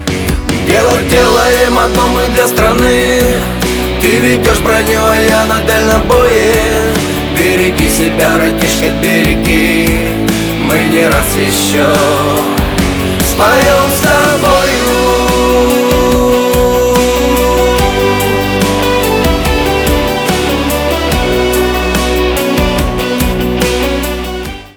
шансон
барабаны